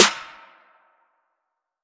Ghs_snr.wav